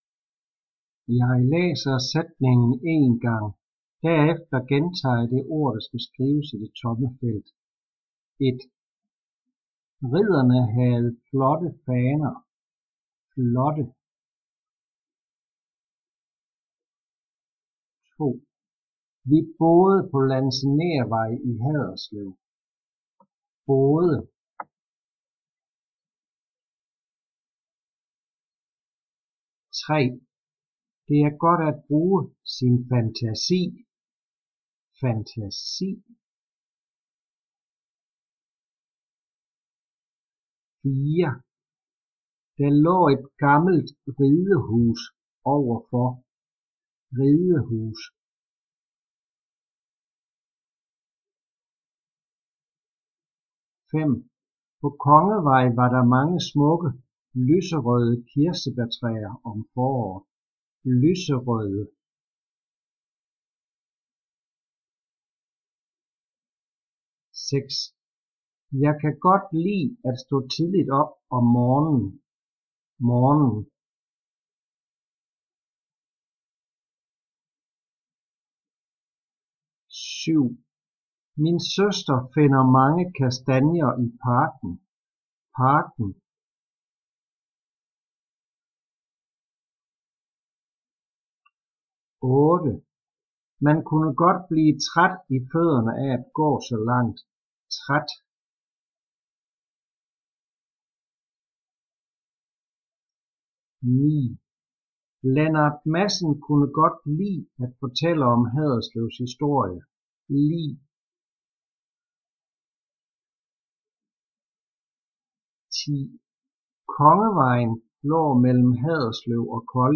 Trin 2 - Lektion 1 - Diktat
Jeg læser sætningen én gang. Derefter gentager jeg det ord, der skal skrives i det tomme felt.